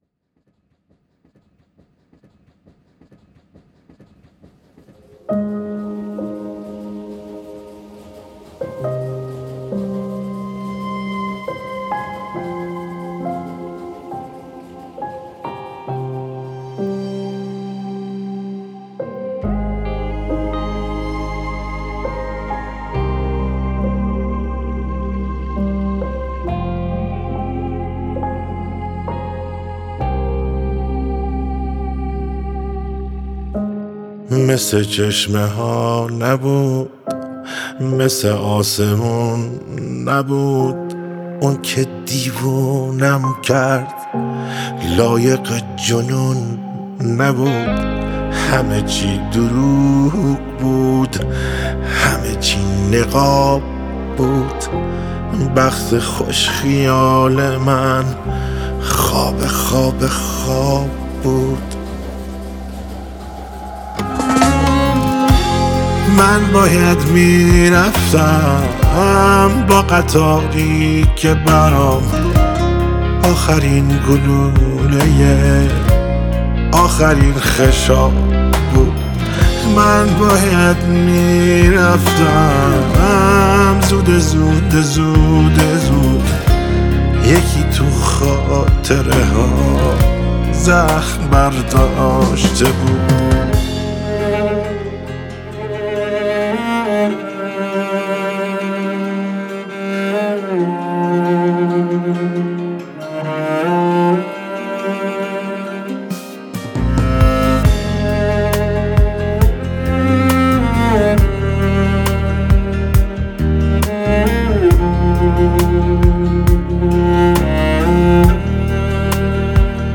آهنگ غمگین و احساسی
با صدای دلنشین